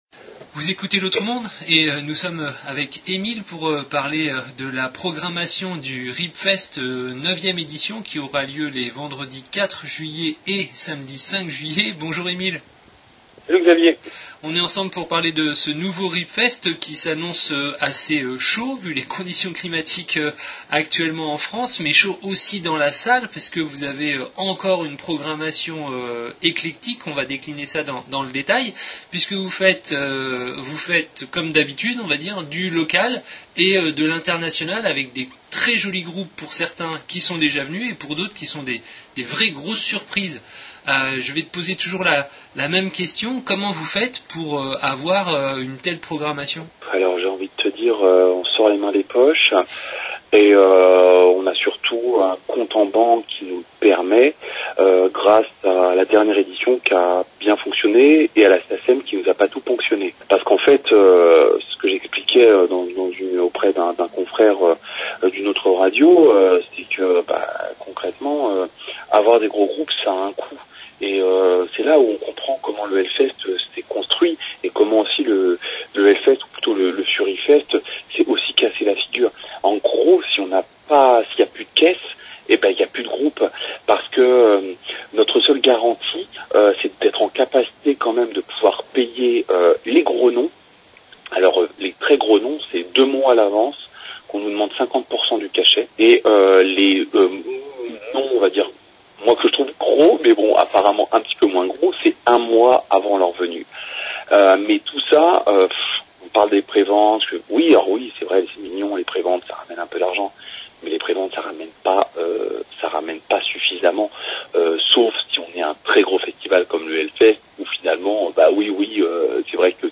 Riip Fest 2025 - 9ème édition - présentation - itw
interview de présentation de la 9ème édition du Riip Fest